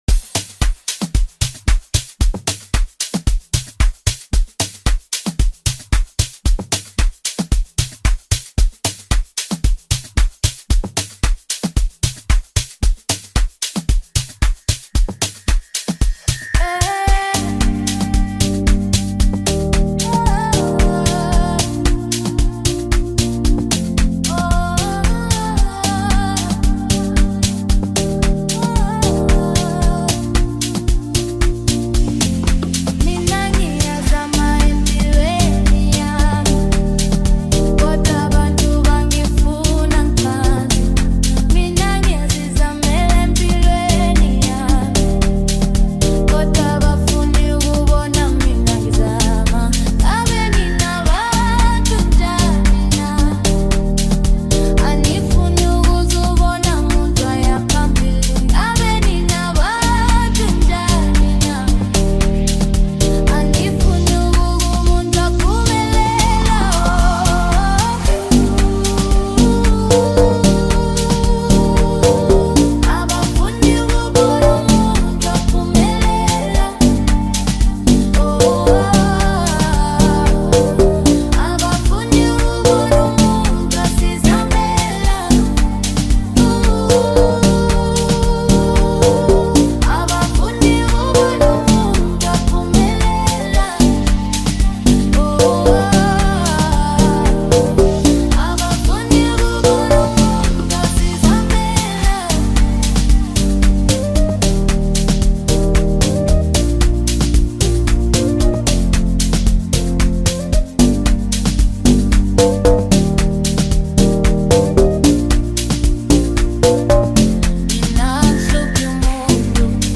Amapiano, DJ Mix, Gqom
South African singer